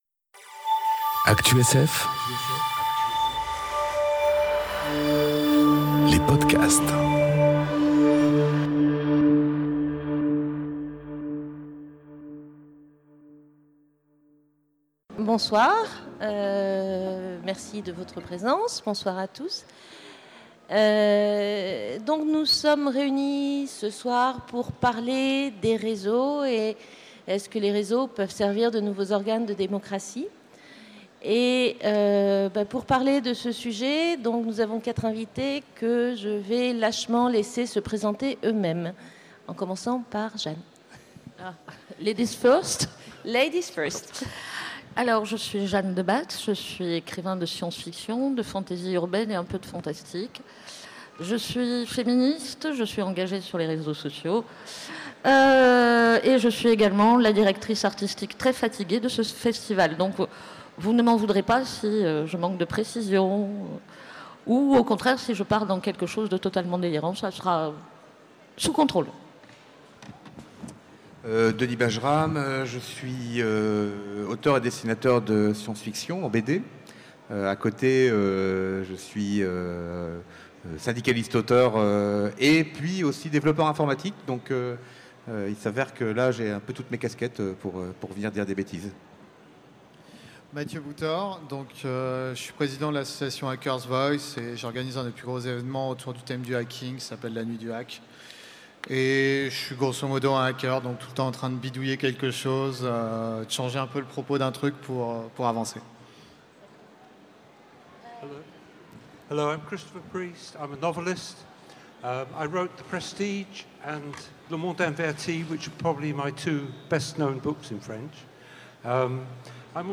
Conférence Les réseaux, nouveaux organes de démocratie ? enregistrée aux Utopiales 2018